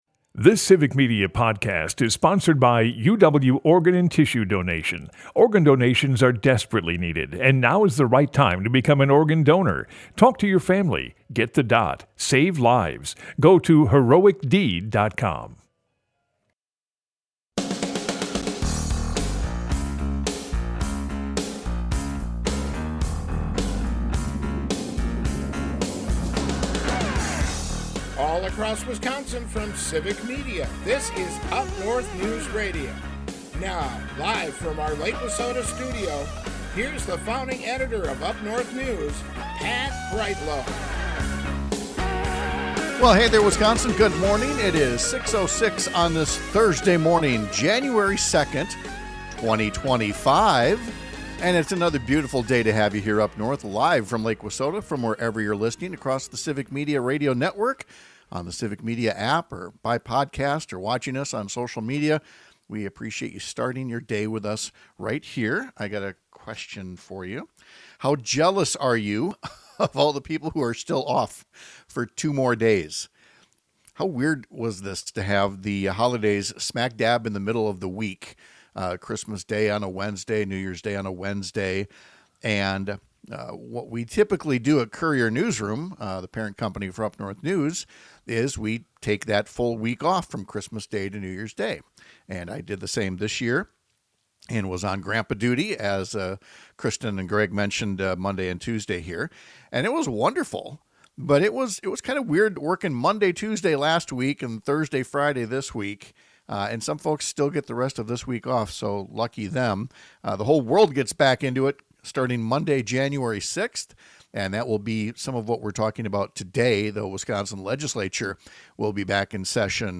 Pat Kreitlow is a longtime Wisconsin journalist and former state legislator who lives in and produces his show from along Lake Wissota in Chippewa Falls.
Broadcasts live 6 - 8 a.m. across the state!